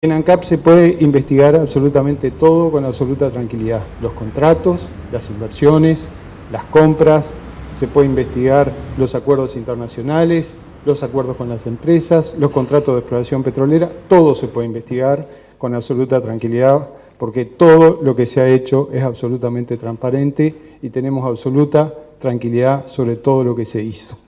El vicepresidente Raúl Sendic dijo en conferencia de prensa que no tiene "nada que ocultar";.